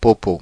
Prononciation
Synonymes (enfantin) caca (enfantin) fèces (enfantin) caquette Prononciation France (Paris): IPA: [po.po] Le mot recherché trouvé avec ces langues de source: français Traduction 1.